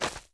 dog_god_walk.wav